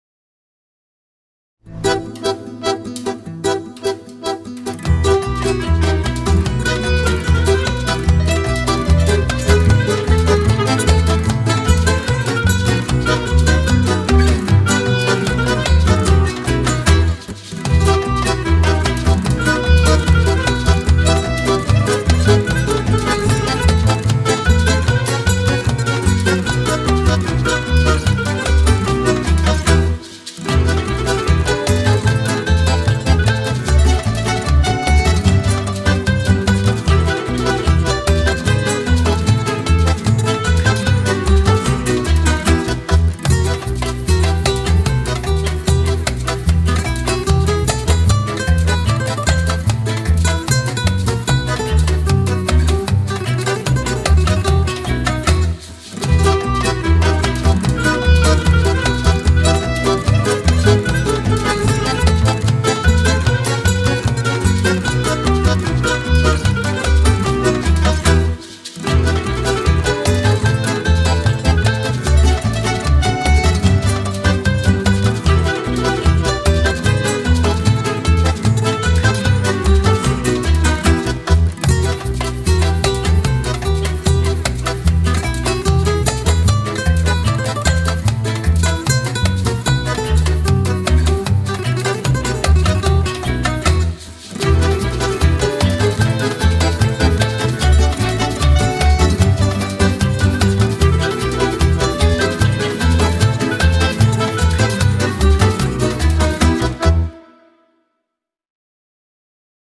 tema dizi müziği, mutlu neşeli eğlenceli fon müziği.